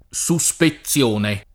SuSpeZZL1ne], suspizione [